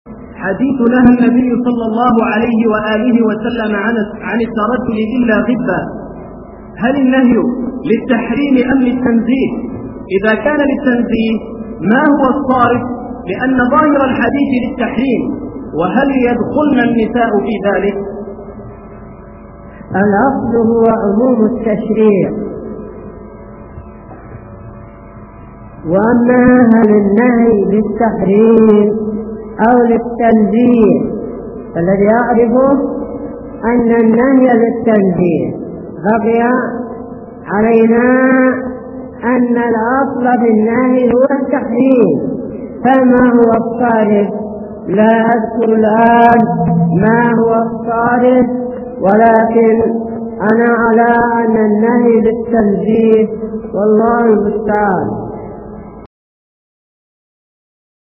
| فتاوى الشيخ مقبل بن هادي الوادعي رحمه الله
------------- من شريط : ( أسئلة نساء البريقة )